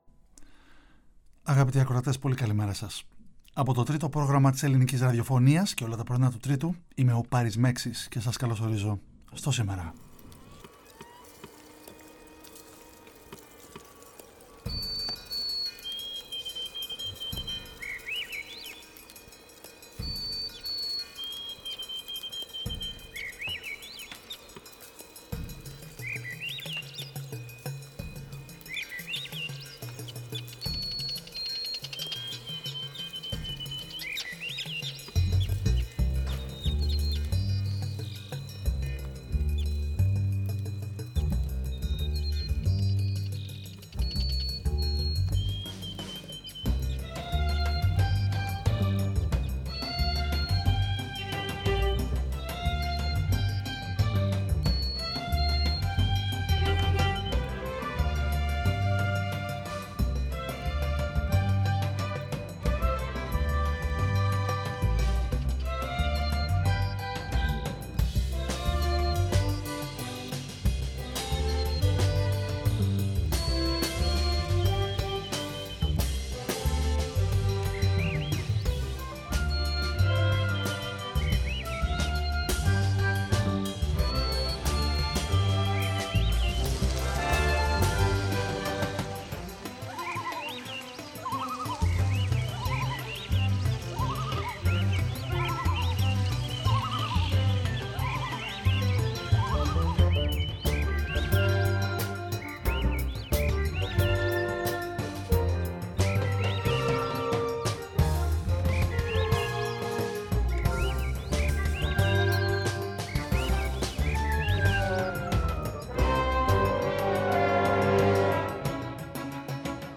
παρουσιάζει, με χιούμορ και θετική διάθεση, μουσική από όλο το φάσμα της ανθρώπινης δημιουργίας